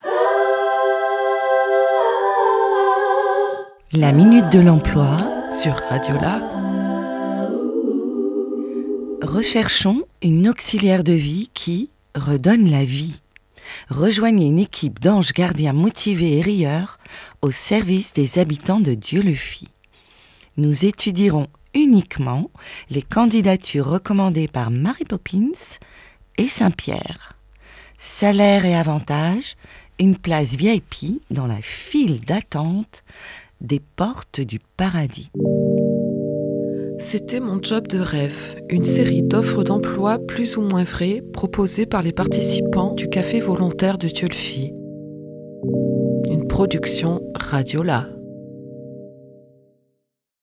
« Mon job de rêve » est une série d’offres d’emploi décalées, écrite et enregistrée par des participant-es du Café volontaire de Dieulefit en juillet 2024.